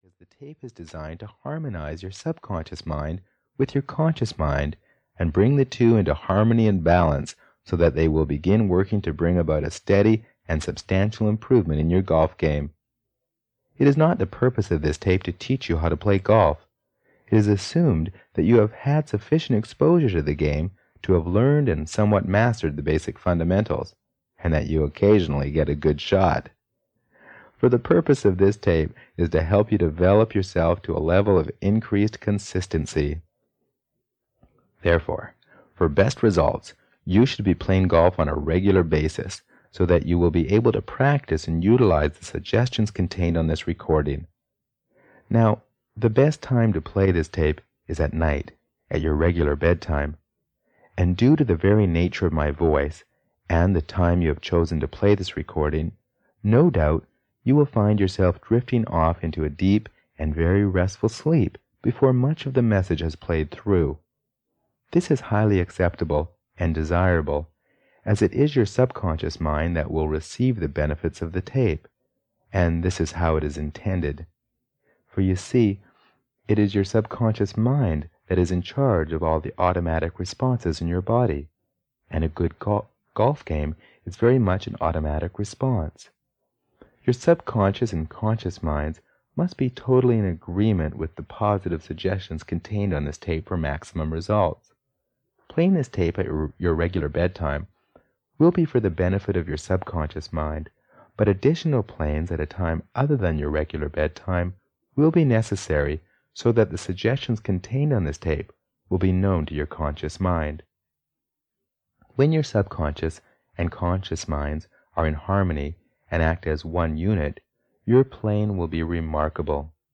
Play Better Golf (EN) audiokniha
Ukázka z knihy